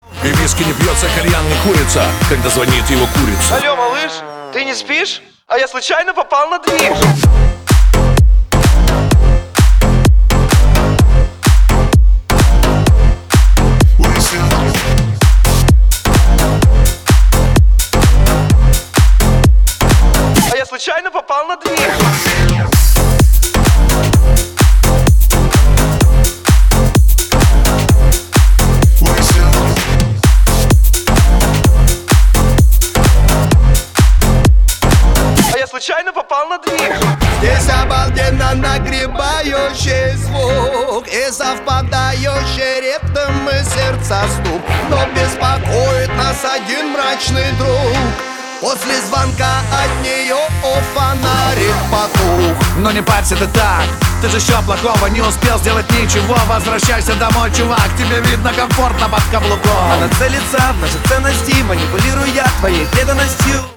• Качество: 320, Stereo
громкие
релакс